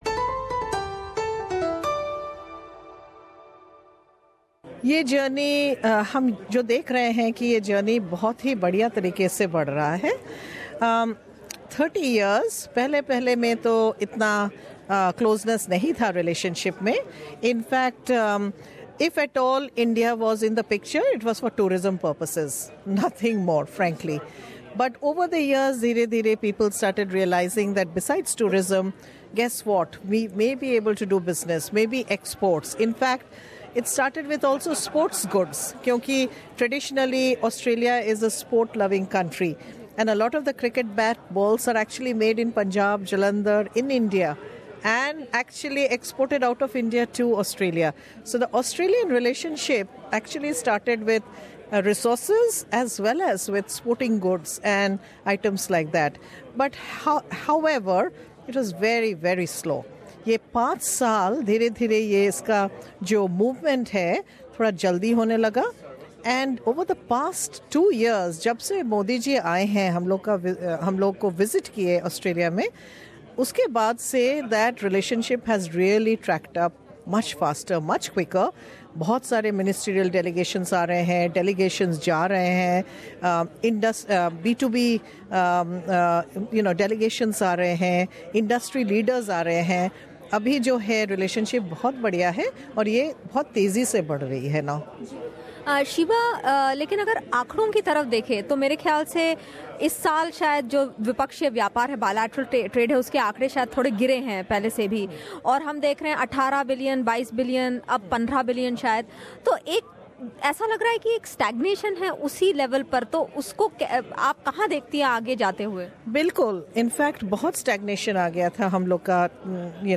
Australia India Business Council (AIBC) recently held its 30th Annual Dinner in Sydney.